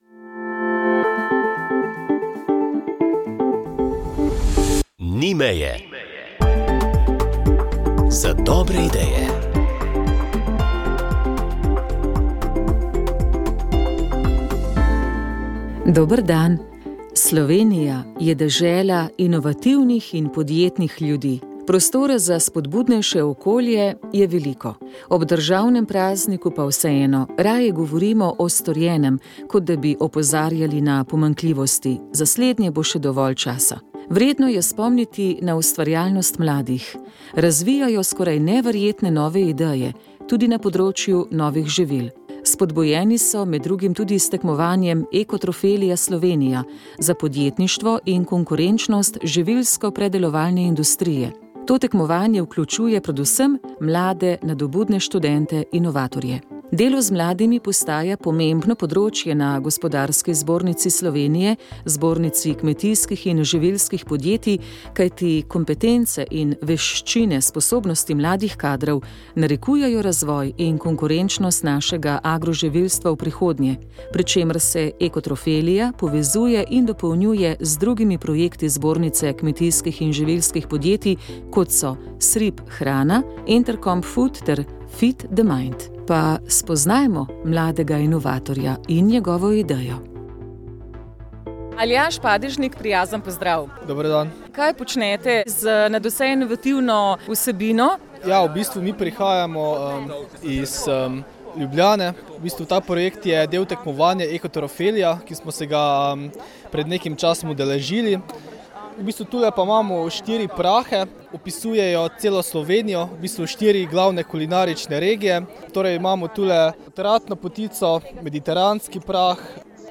Radijska kateheza